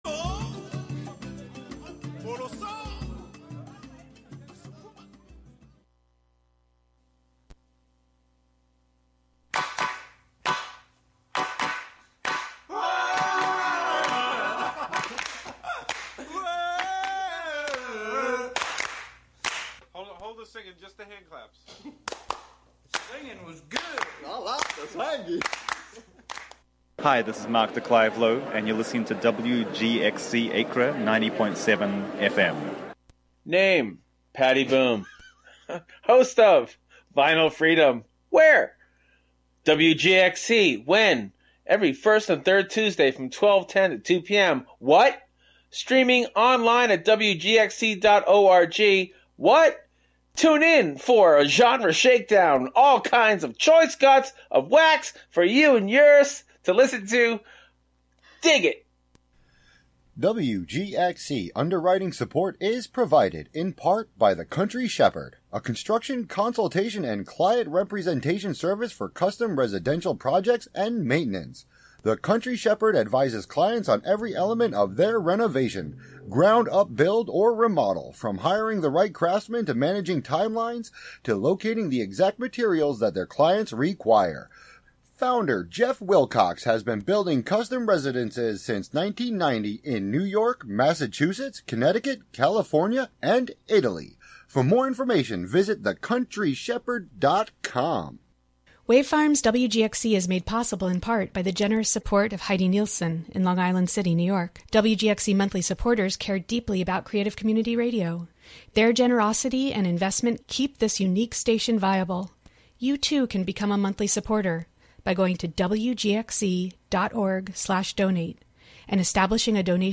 saxophonist/composer extraordinaire
jazz titan